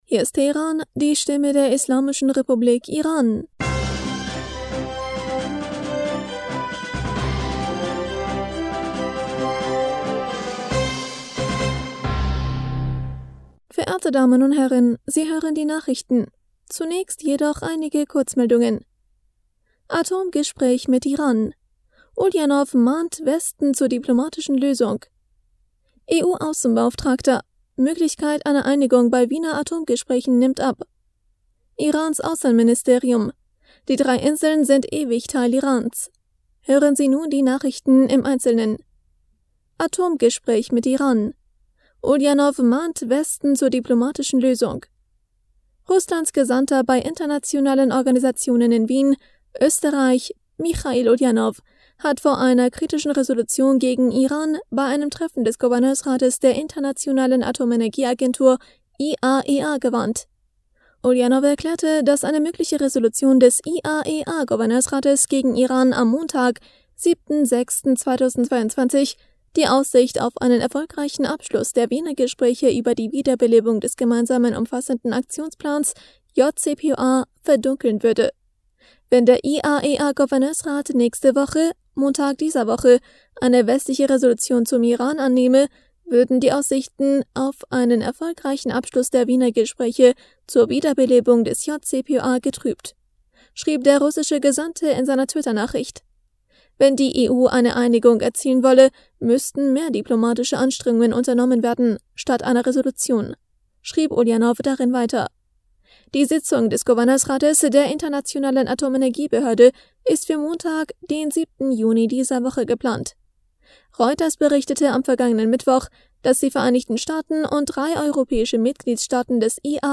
Nachrichten vom 5. Juni 2022